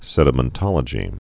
(sĕdə-mən-tŏlə-jē, -mĕn-)